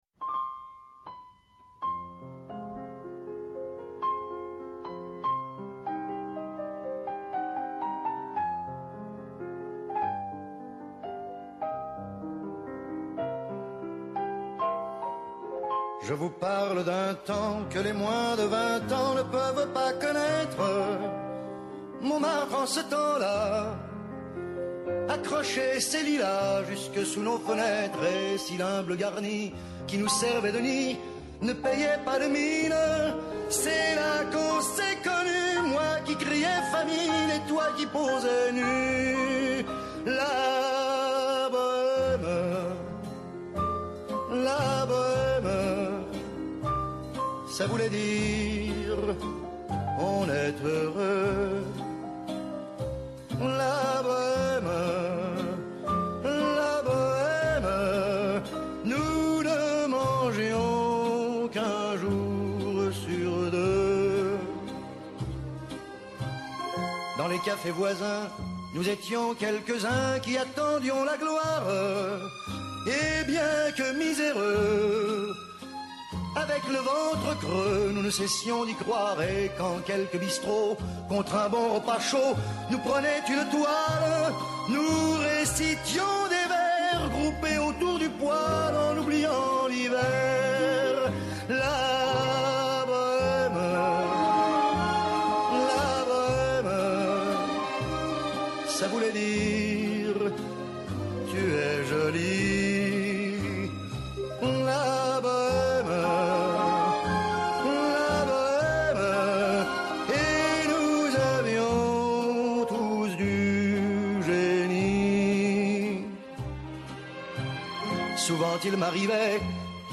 Εκτάκτως σήμερα 10:00-11:00 το πρωί καλεσμένος στο studio